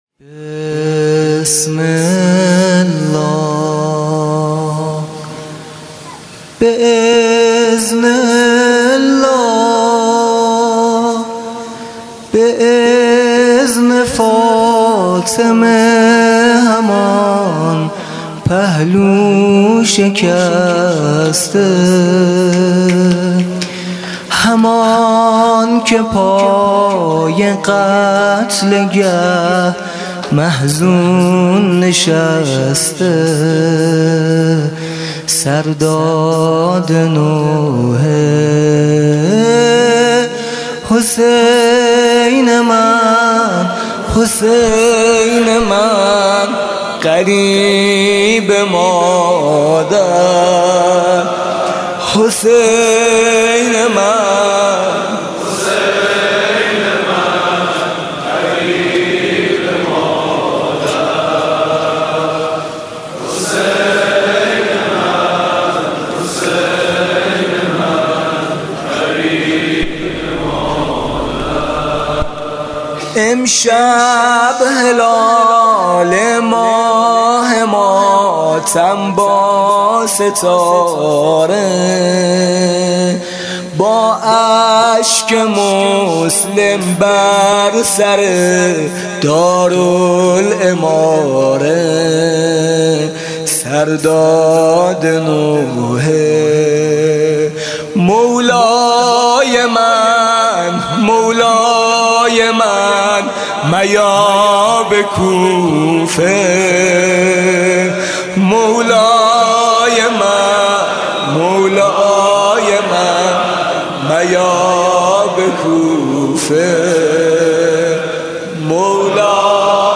«محرم 1396» (شب اول) دم آغازین: بسم الله به اذن الله